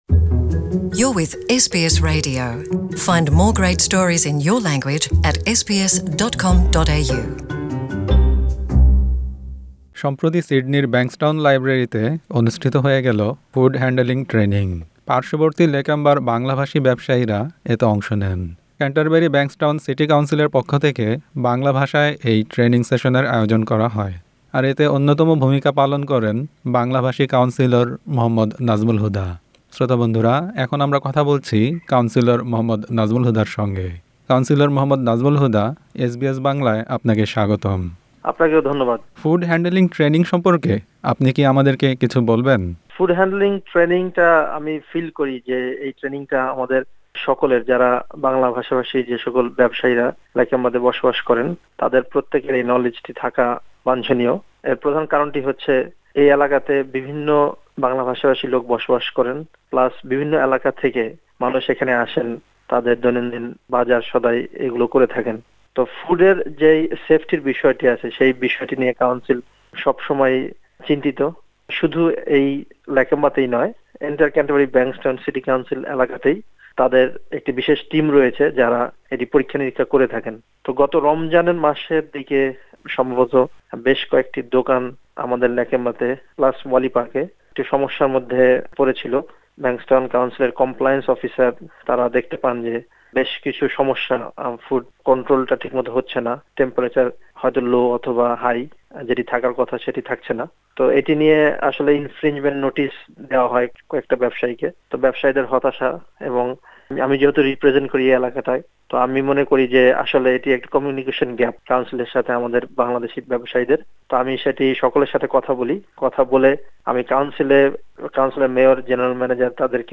Councillor Mohammad Nazmul Huda spoke with SBS Bangla about this event.